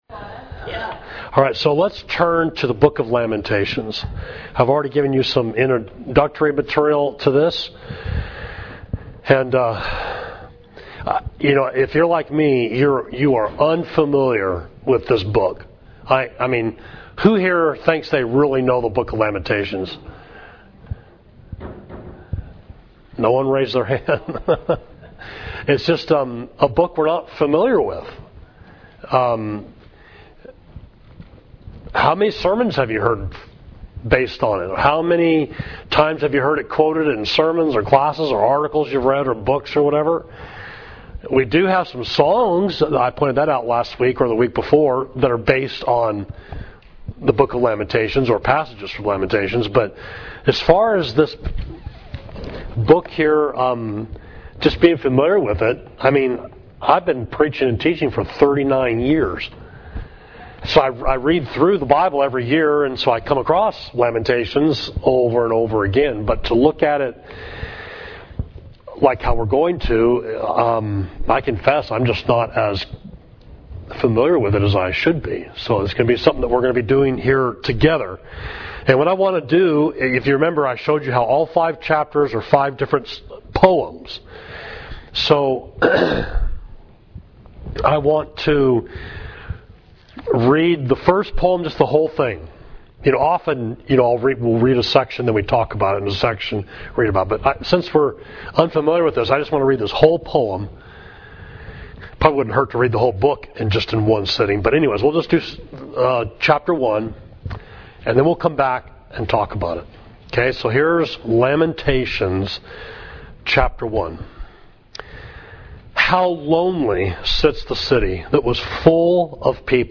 Class: Mourning Jerusalem, Lamentations 1